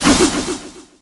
metal_swipe_03.ogg